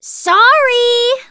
jess_kill_06.wav